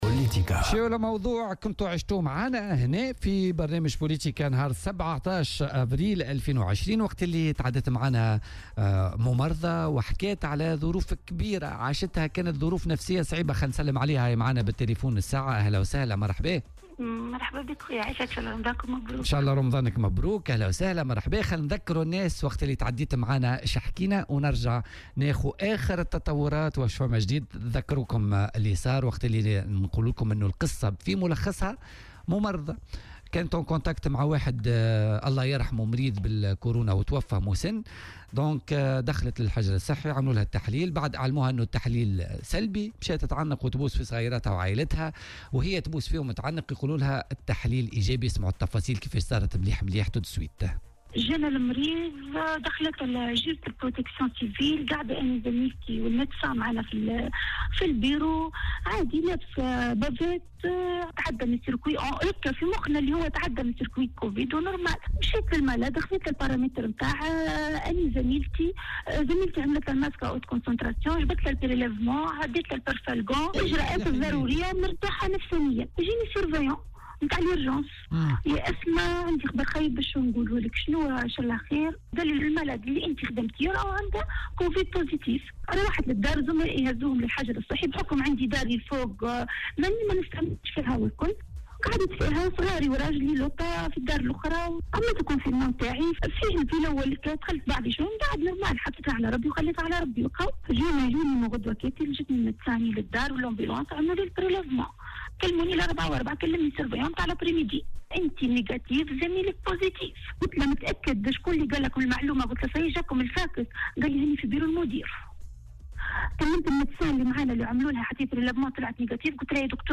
وأضافت في مداخلة لها اليوم في برنامج "بوليتيكا" أنها أتمت فترة الحجر الصحي وأن حالتها الصحية مستقرة، لكنها في انتظار إجراء التحليل الأخير للتثبت من سلامتها.